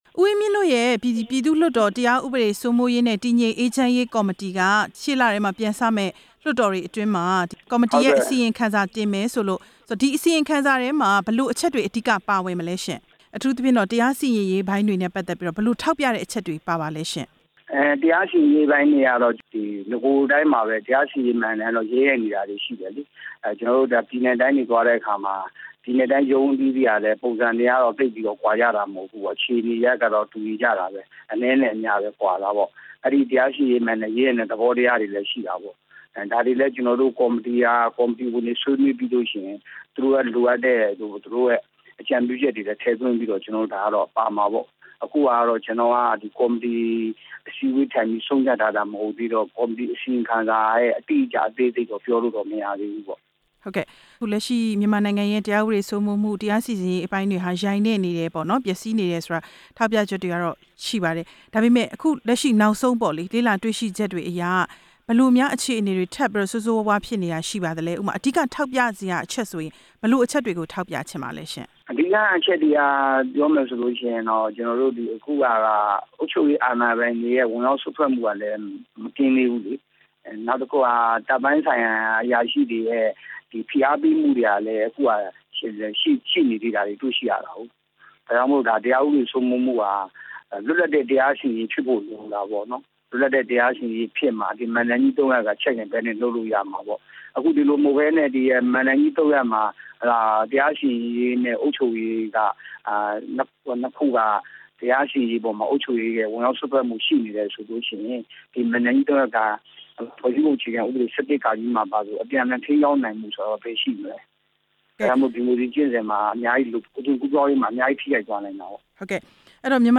မြန်မာနိုင်ငံရဲ့ တရားစီရင်ရေး အုပ်ချုပ်ရေး ဝင်ရောက်စွက်ဖက်မှု မေးမြန်းချက်